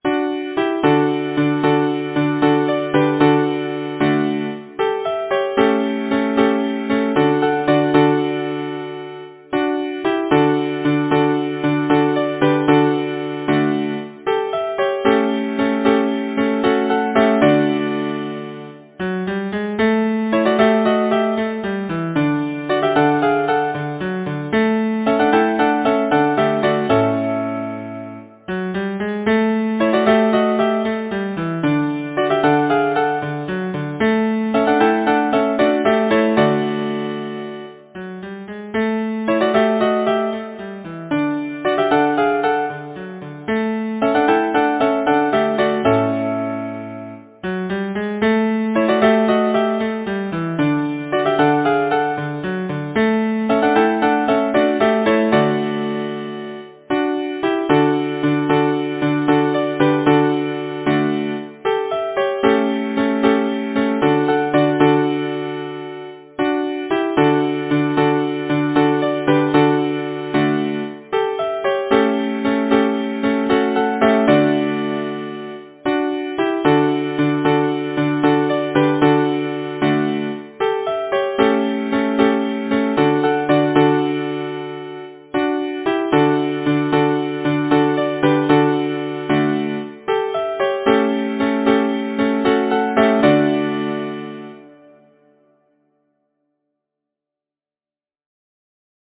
Title: Coming of Spring Composer: S. Wesley Martin Lyricist: Number of voices: 4vv Voicing: SATB Genre: Secular, Partsong
Language: English Instruments: A cappella